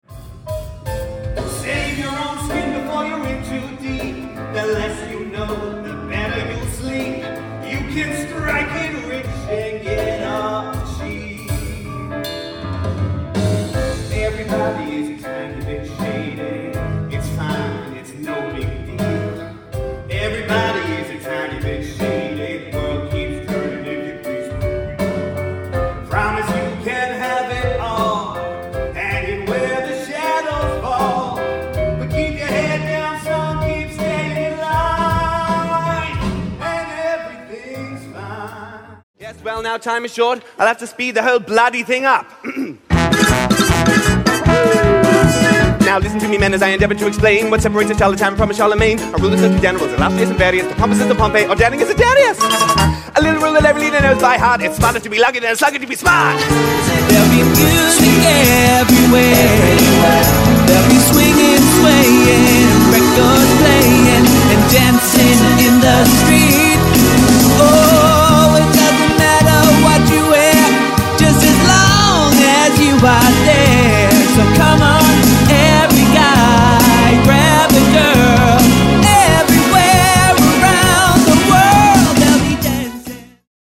Singing